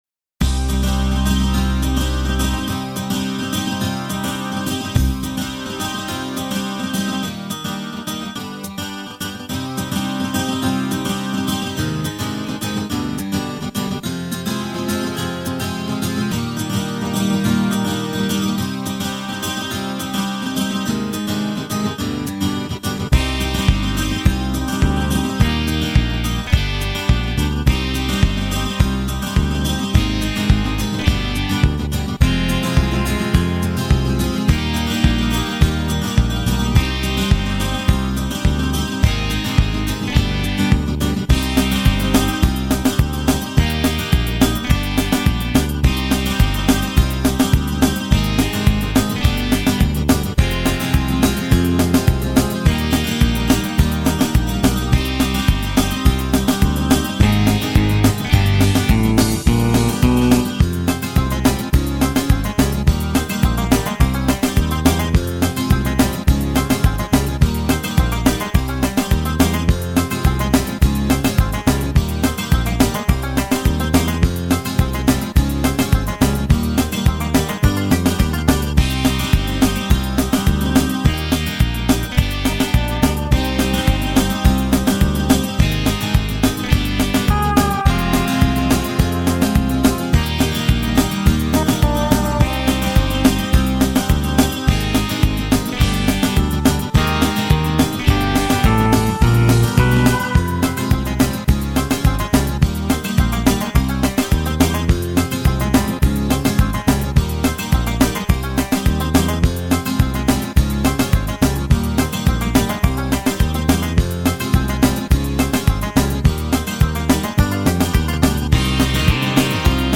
минусовка версия 42161